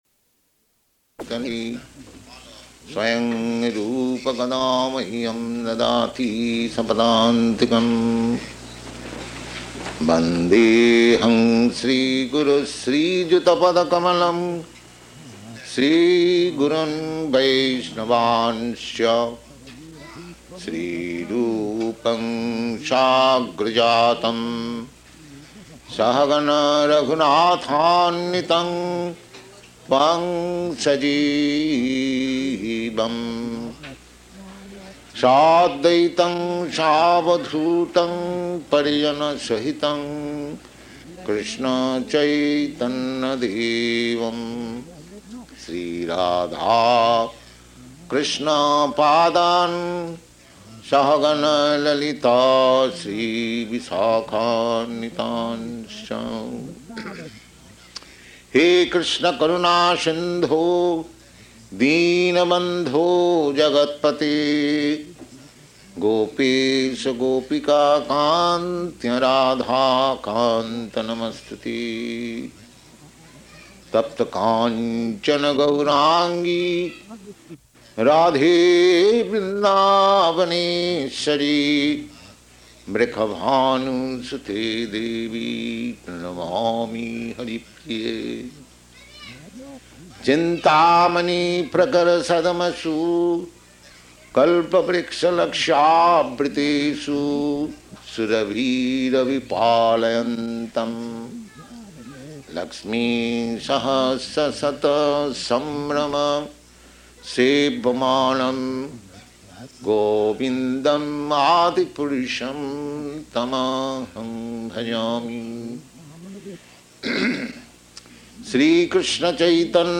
Location: San Francisco
Prabhupāda: [chants maṅgalācaraṇa prayers]